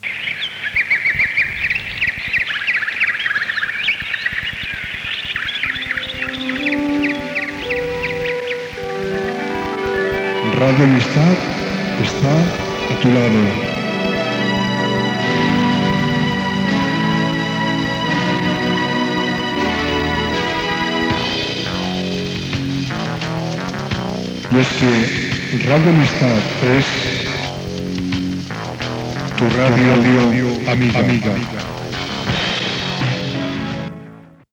Identificació de l'emissora
FM